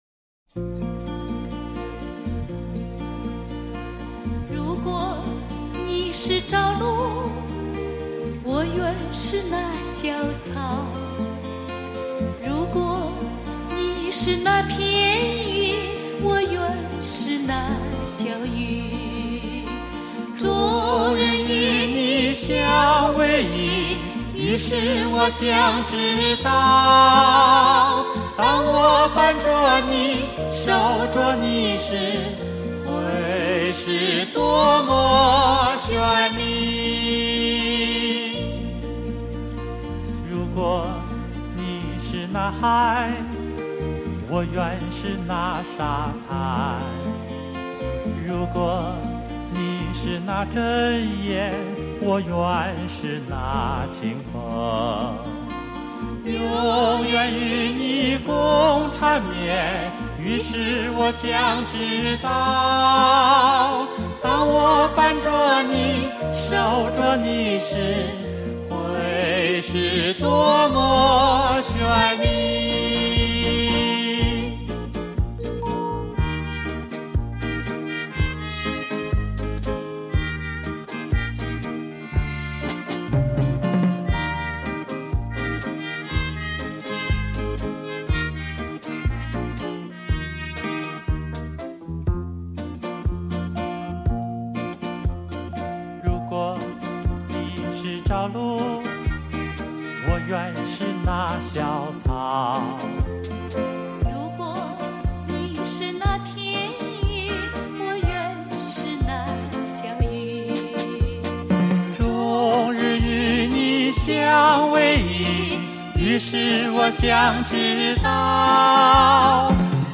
中国优秀男女声二重唱
和谐和声 默契演绎！